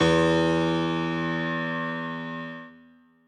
b_basspiano_v127l1-2o3e.ogg